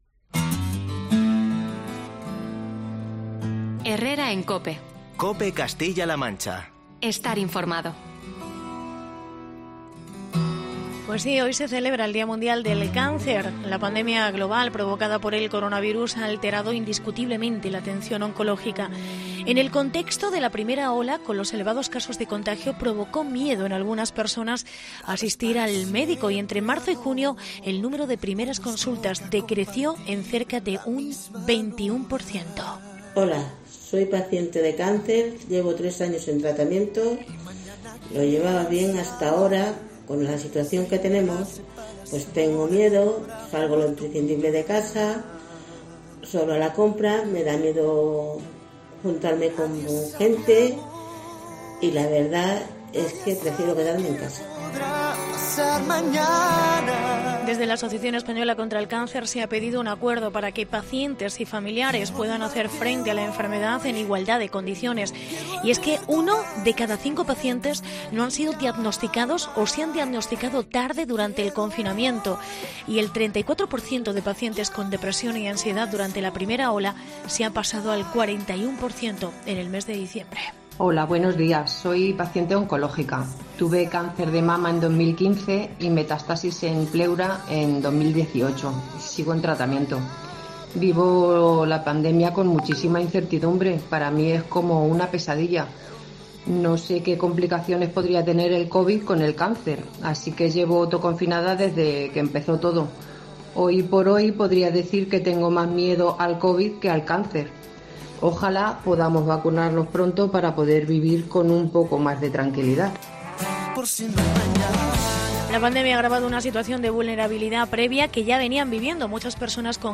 Testimonios de pacientes con cáncer de Talavera de la Reina